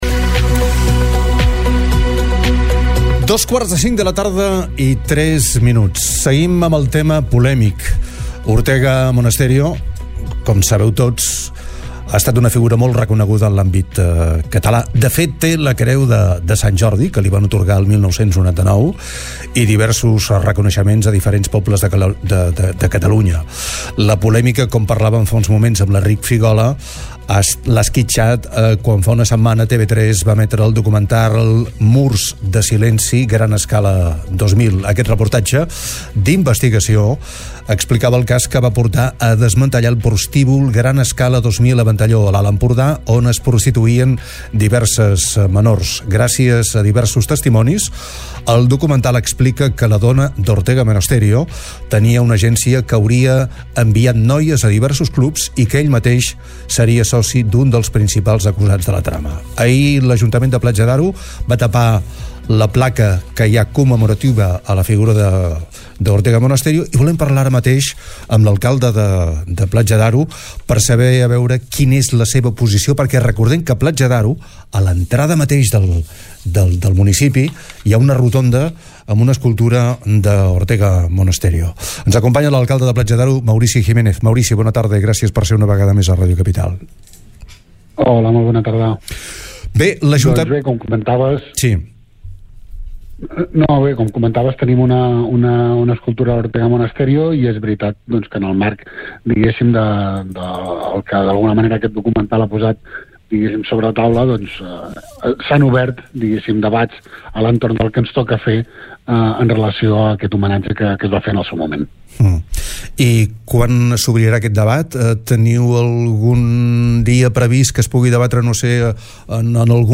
Maurici Jimenez, alcalde de la població, en declaracions a, TARDA CAPITAL, demana un temps de reflexió per veure com evoluciona la polèmica després de l’emissió  del documental de 3cat on es relacionava l’autor de l’havanera “el meu avi” amb el tràfic de menors.
alcalde-platja-daro.mp3